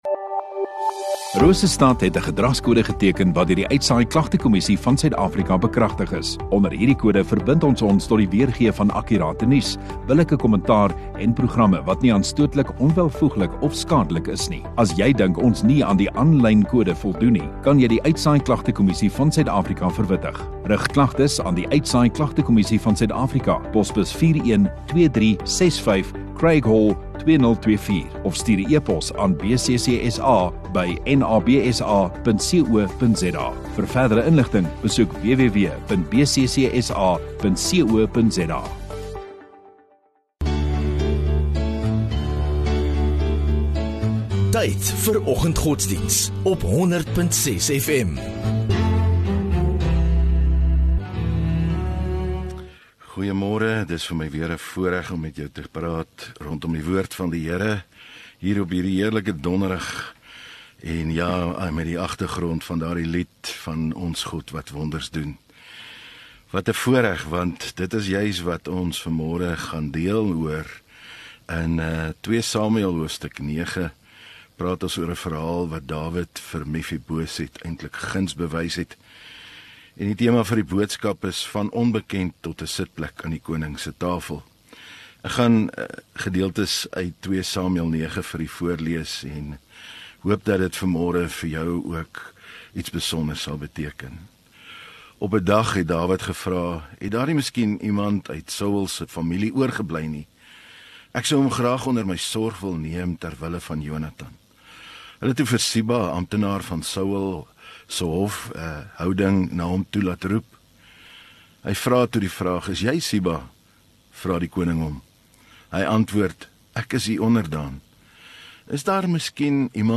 10 Apr Donderdag Oggenddiens